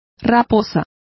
Complete with pronunciation of the translation of vixens.